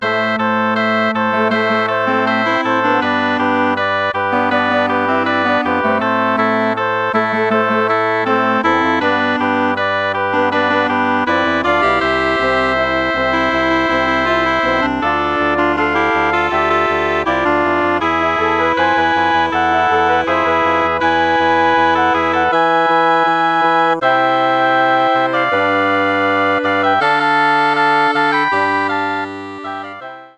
Arrangement / Bläserquintett / Rock/Pop
Bearbeitung für Bläserquintett
Besetzung: Flöte, Oboe, Klarinette (B), Horn (F), Fagott
Arrangement for woodwind quintet